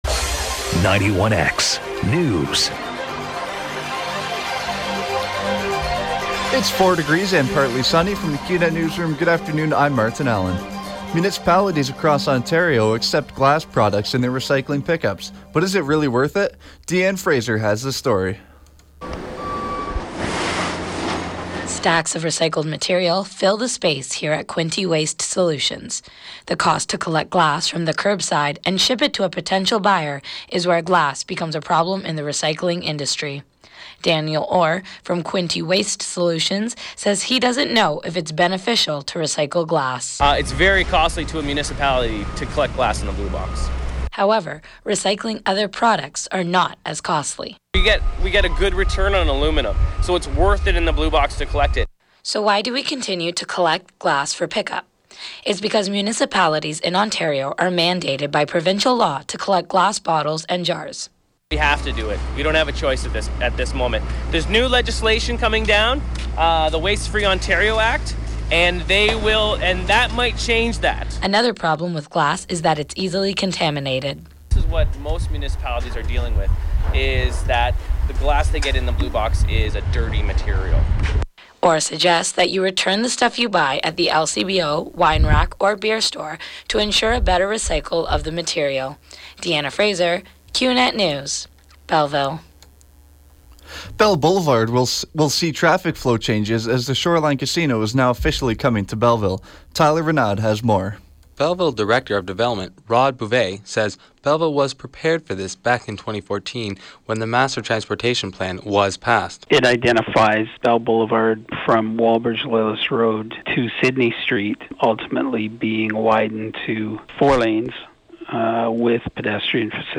91X newscast – Thursday, Feb. 4, 2016 – 12 noon